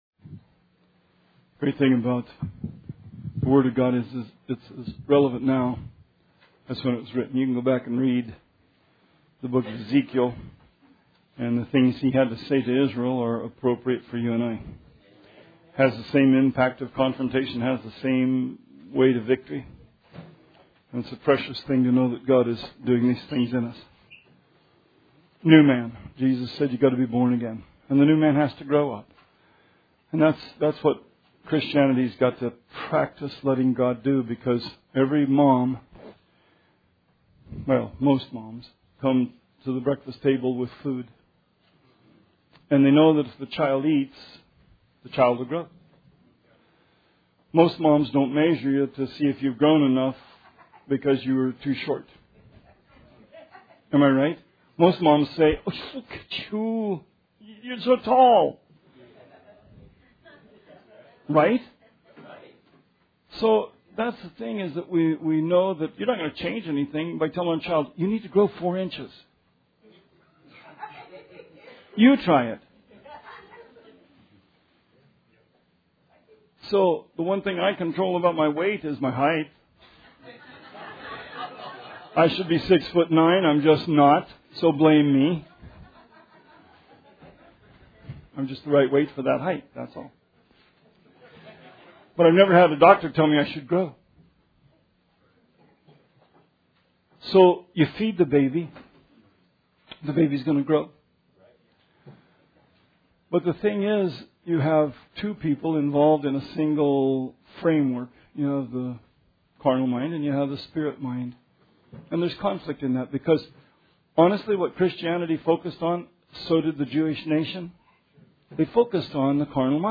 Sermon 5/21/17